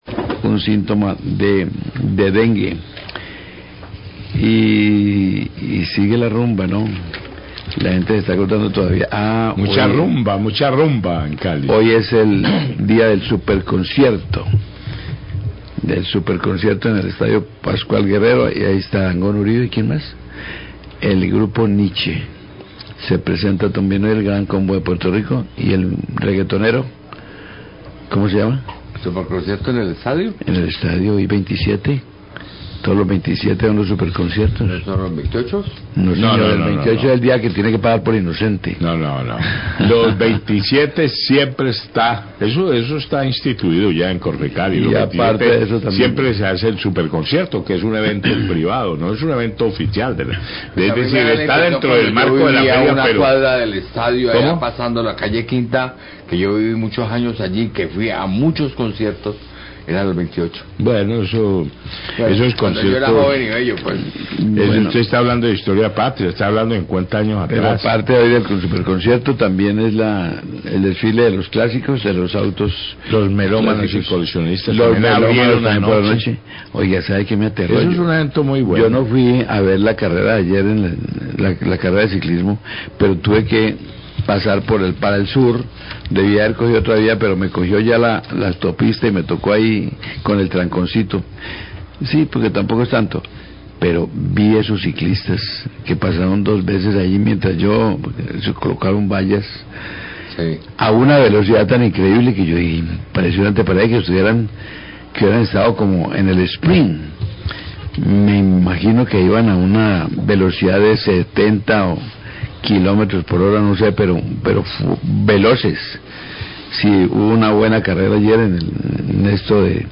Radio
Periodista comenta que pasó por la autopista durante el Gran Prix de Ciclismo y se sorprendió por la velocidad de los ciclistas. También recuerda los eventos de la Feria de Cali como el Superconcierto, Desfile de Autos Clásicos y el Desfile de Cali Viejo.